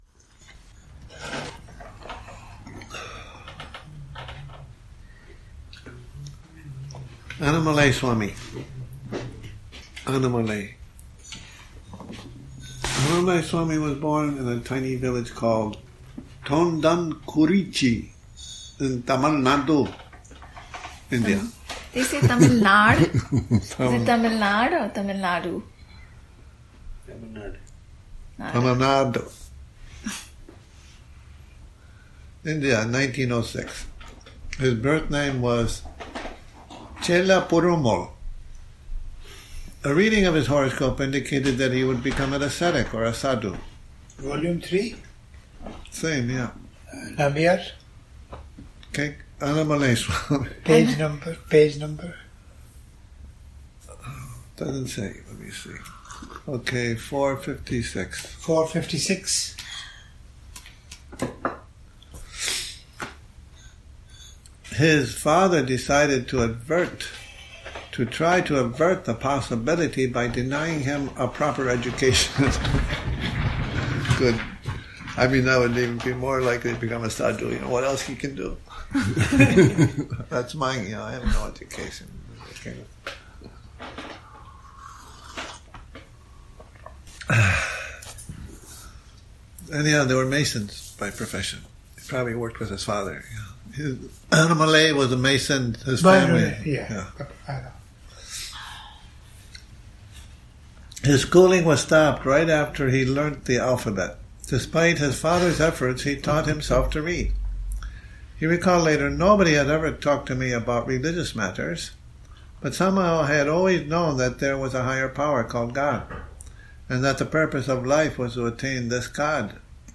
a reading from "Annamalai Swami"